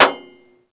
Index of /server/sound/vj_impact_metal/bullet_metal
metalsolid4.wav